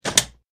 door.mp3